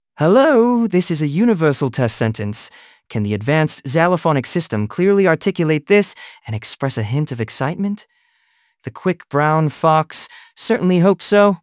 Open-Source-TTS-Gallary / samples /Zyphra_Zonos-v0.1-transformer /generated-audio.wav